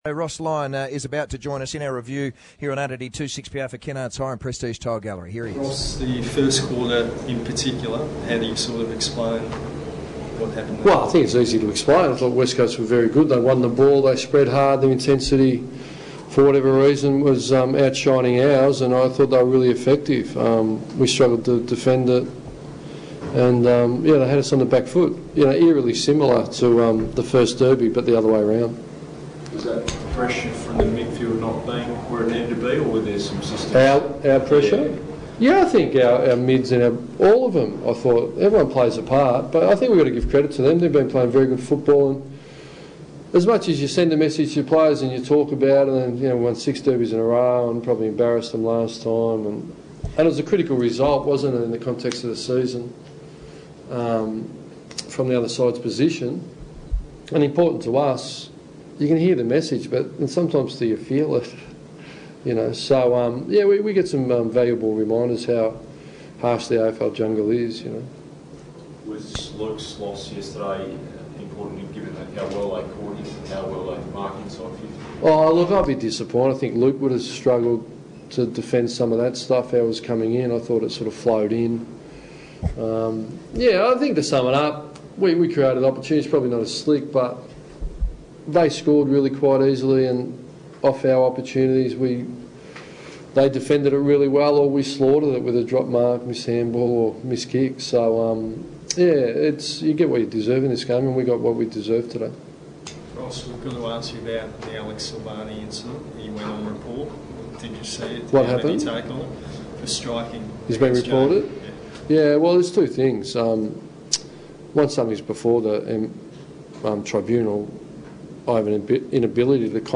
Ross Lyon Post Game Press Conference